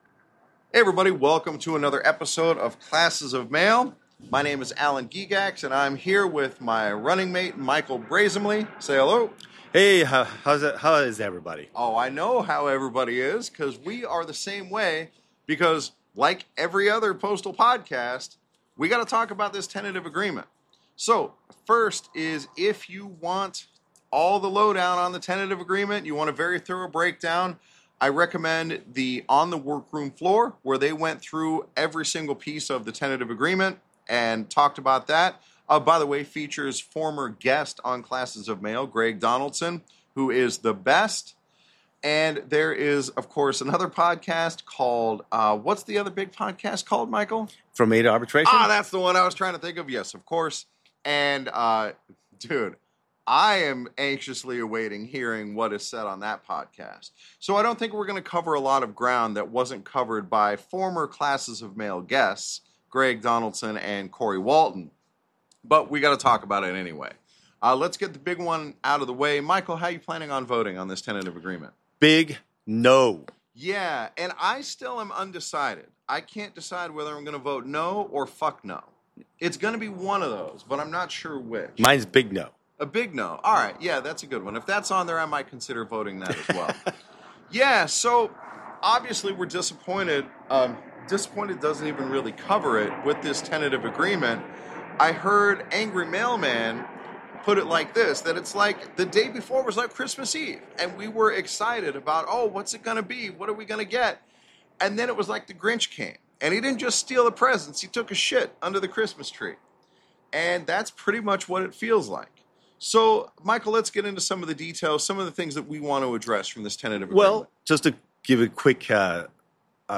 This episode was recorded in my back yard, so we don't have any annoying echo. On the downside, we have to compete with aircraft noise.